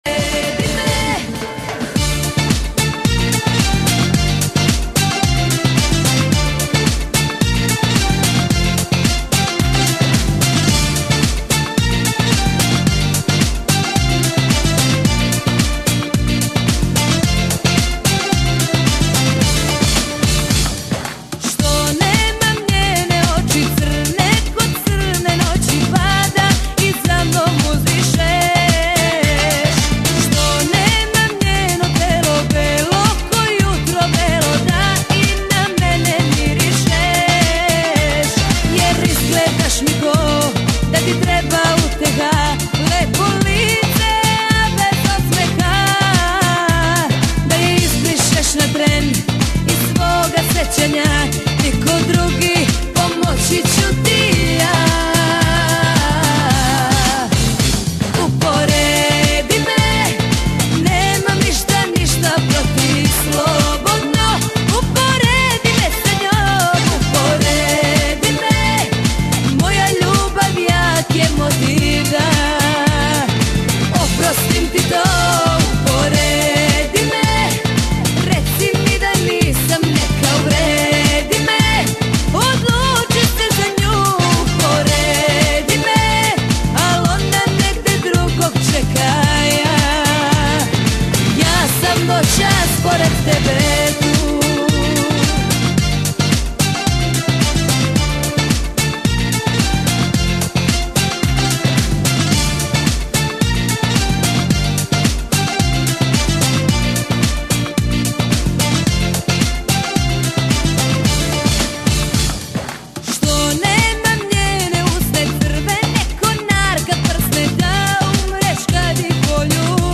Для любителей современной сербской эстрадной музыки.